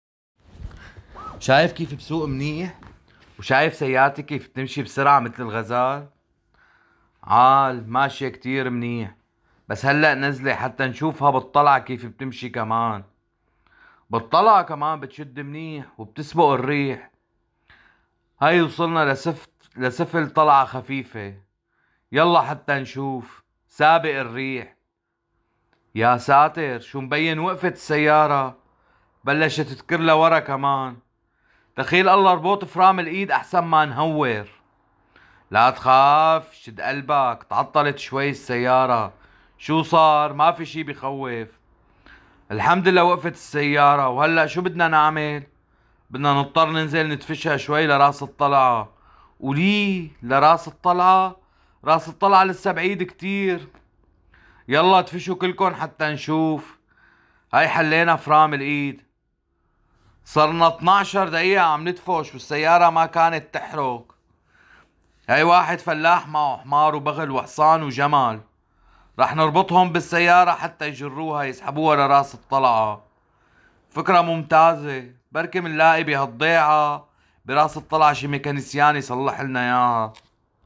Hörprobe Syrisch-Arabisch
Lehrbuchtext: Eine Autopanne
hoerprobe-syrisch-arabisch-lehrbuchtext-eine-autopanne.mp3